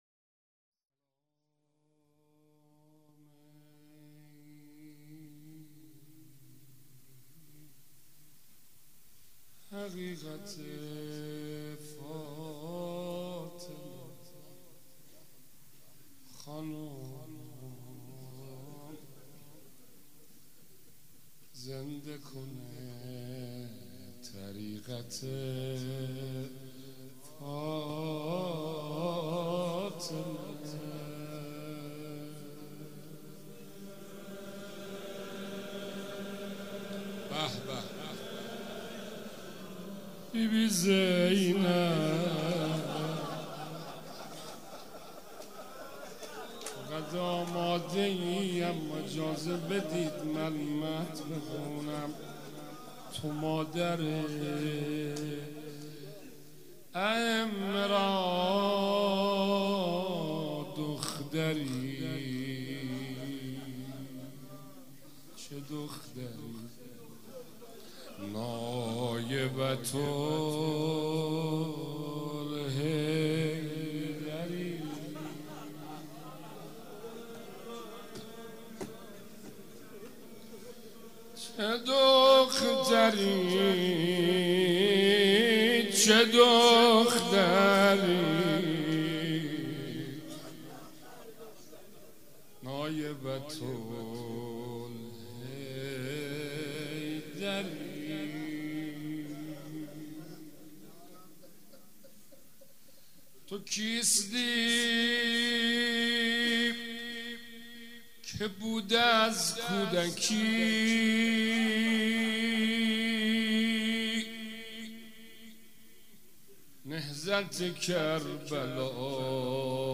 روضه
مراسم شهادت حضرت زینب کبری(سلام الله عليها) چهارشنبه ٢٣فروردین١٣٩٦ مجتمع فرهنگی مذهبی ریحانة الحسین(س)
روضه اول.mp3